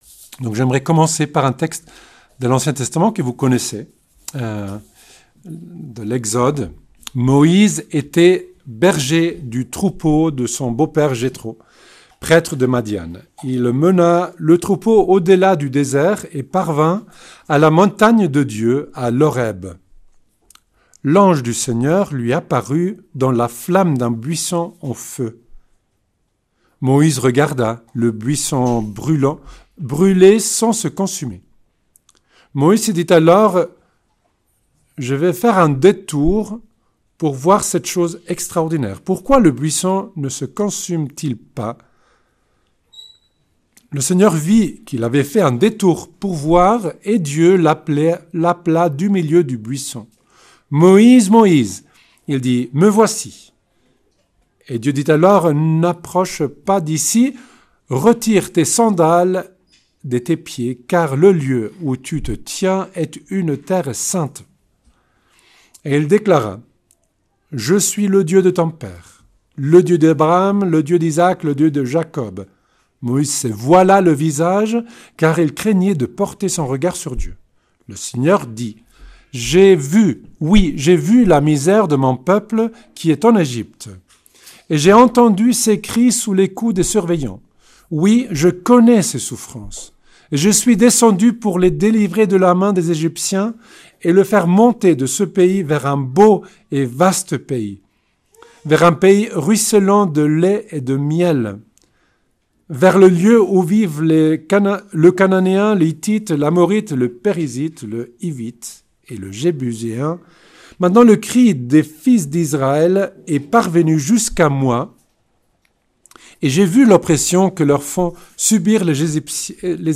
Notre Dame du Laus - Festival Marial